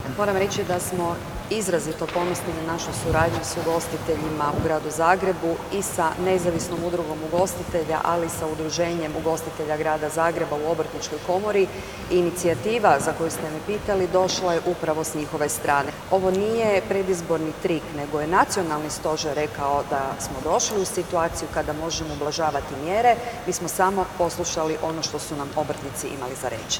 Okršaj u zagrebačkoj Esplanadi otkrio je kako izgleda kada se na jednom mjestu okupi deset političkih oponenata. Izdvojili smo zanimljive odgovore kandidata iz višesatnog sučeljavanja.